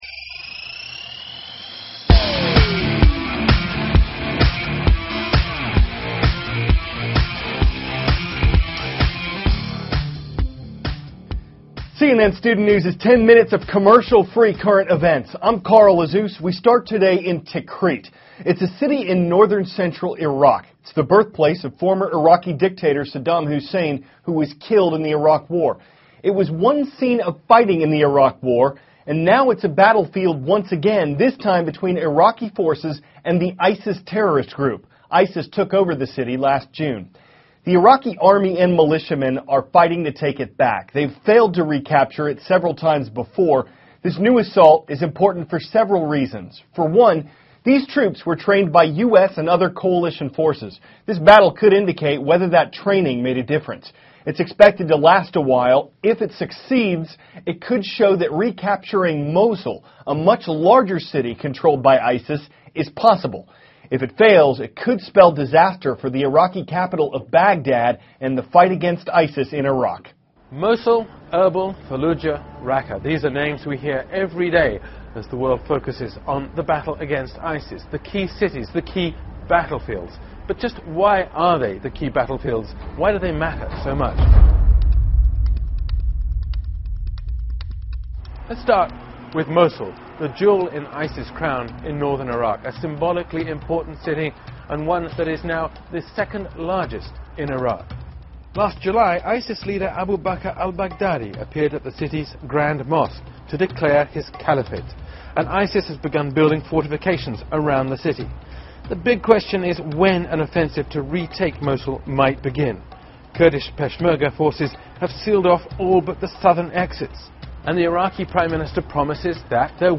(cnn Student News) -- March 3, 2014